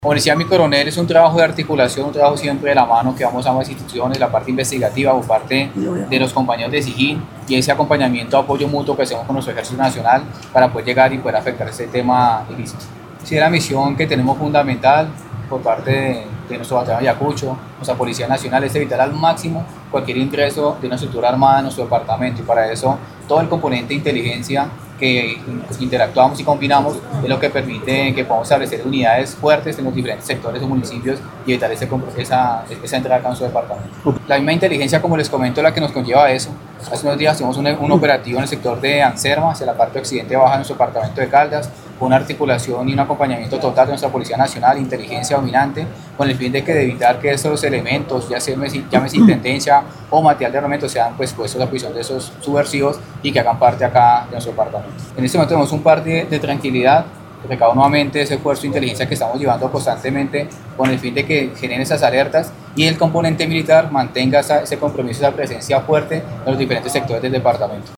Comandante-del-Batallon-de-Infanteria-N°22-Batalla-de-Ayacucho-coronel-Jhon-Fernando-Martinez-Tapias.mp3